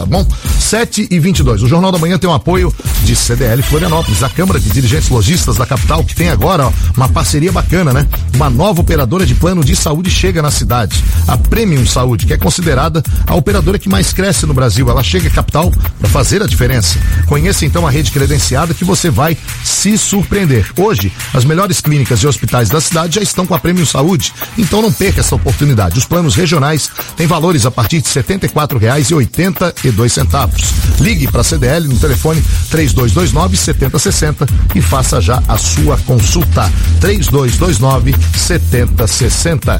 CDL de Florianópolis - Rádio